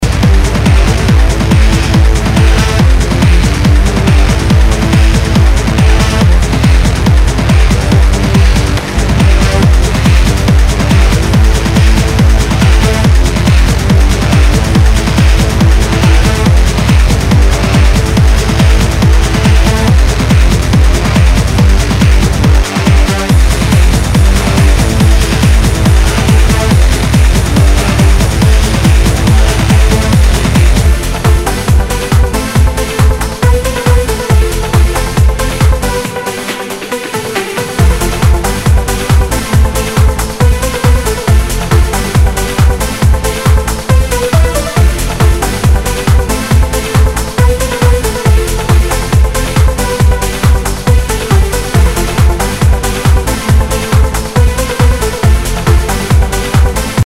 HOUSE/TECHNO/ELECTRO
ナイス！ハード・トランス・テクノ！